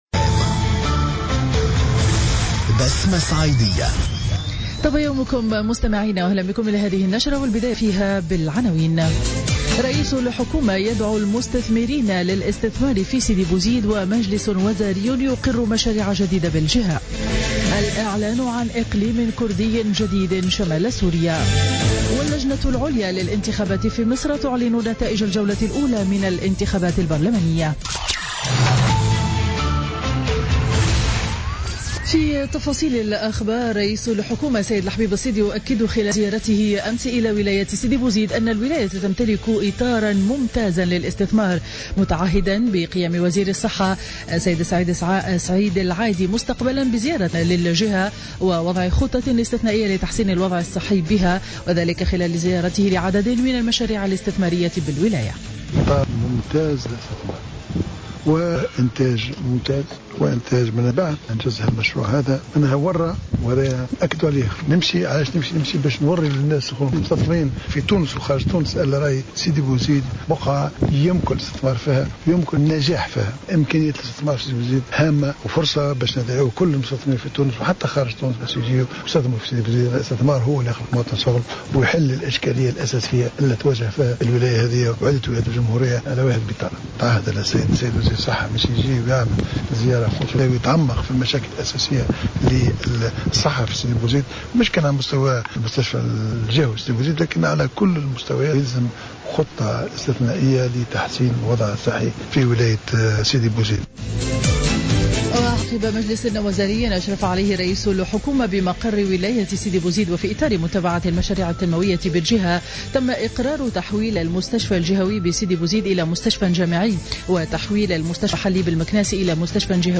نشرة أخبار السابعة صباحا ليوم الخميس 22 أكتوبر 2015